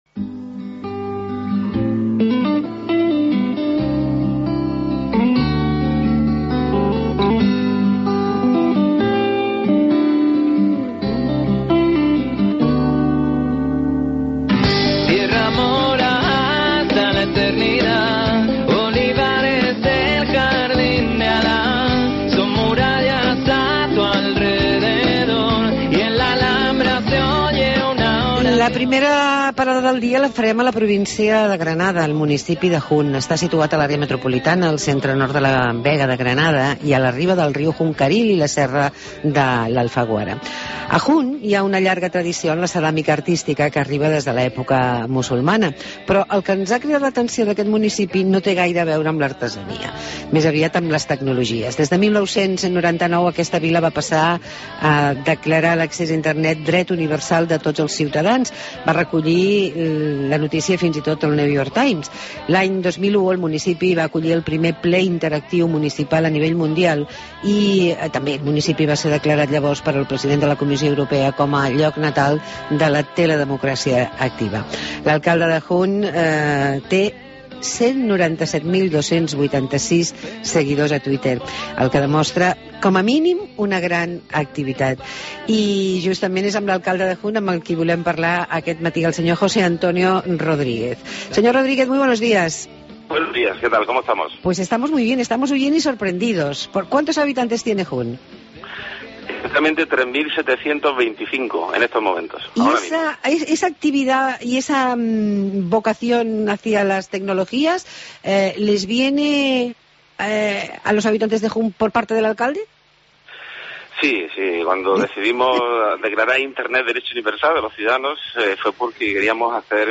Entrevista a José Antonio Rodríguez, alcalde de Jun (Granada)